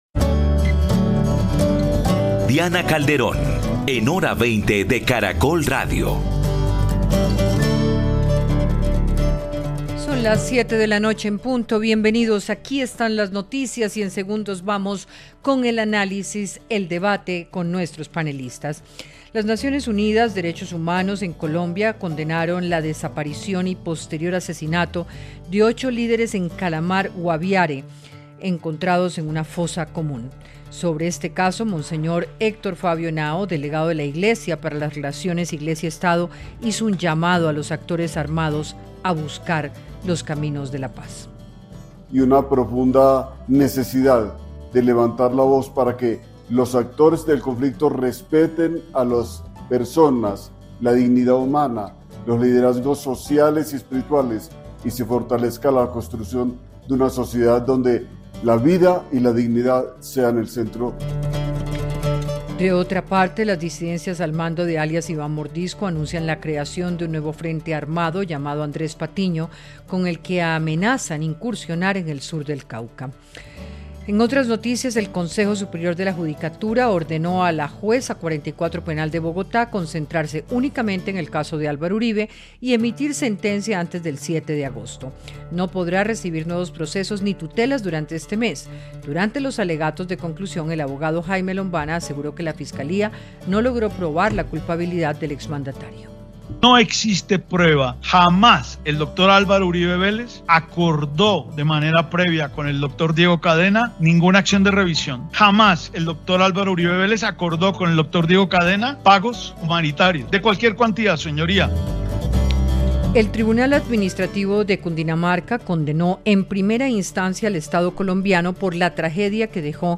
Panelistas analizaron lo que implica en términos de violencia la aparición de una fosa común en Calamar. También debatieron sobre los marcos jurídicos, los acuerdos de sustitución y las capacidades de la Fuerza Pública